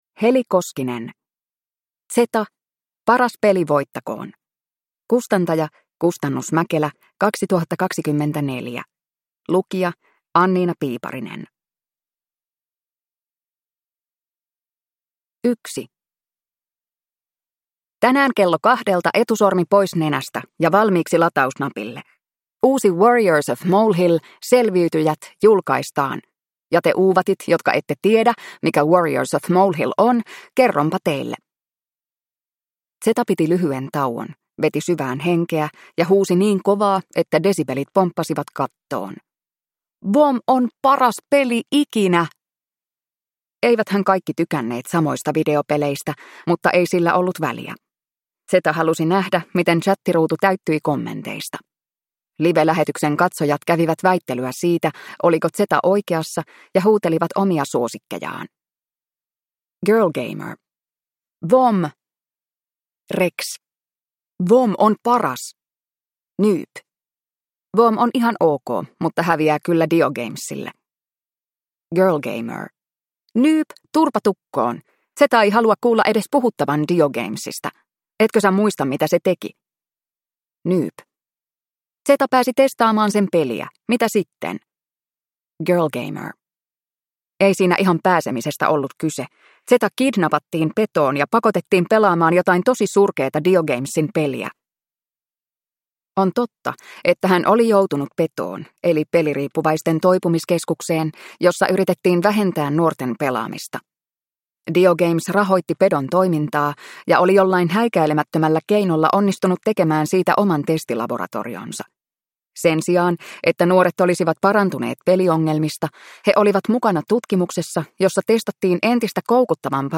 Zeta: Paras peli voittakoon! – Ljudbok